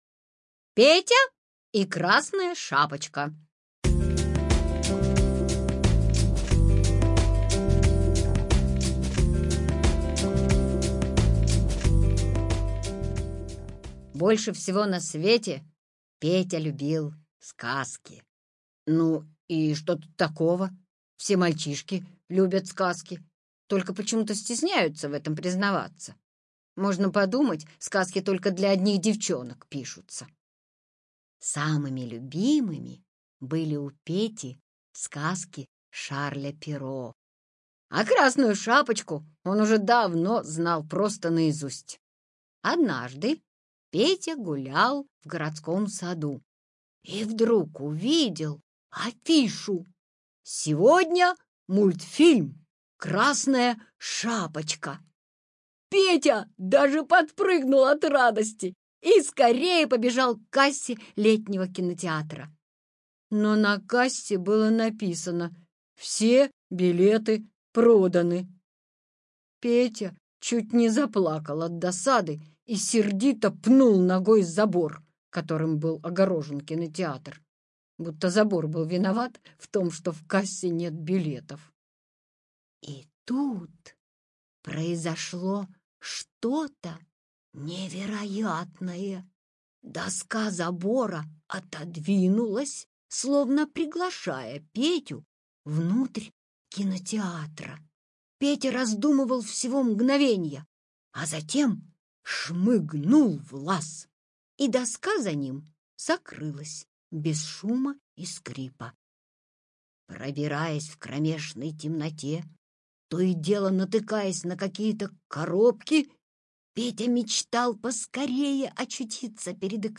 Петя и Красная шапочка - аудиосказка Сутеева В.Г. Слушайте онлайн сказку "Петя и Красная шапочка" Сутеева на сайте Мишкины книжки.